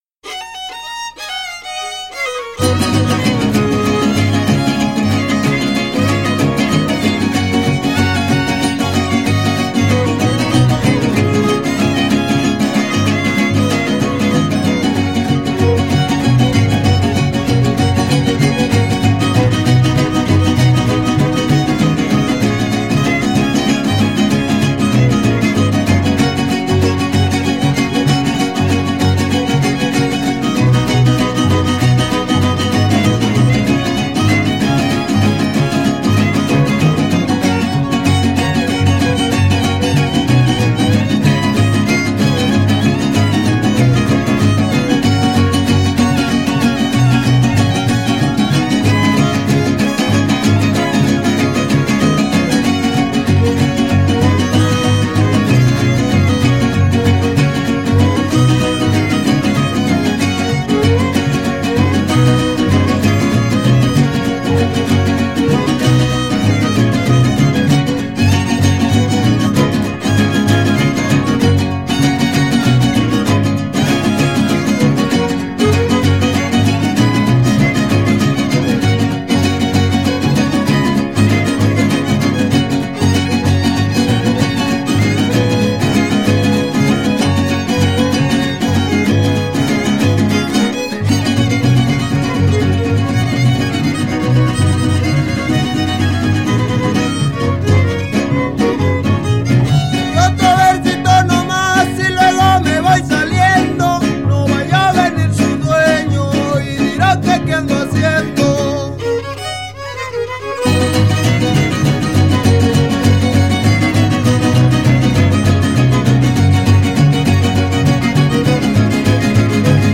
Keywords: arpa grande
folklor mexicano
Grabaciones de campo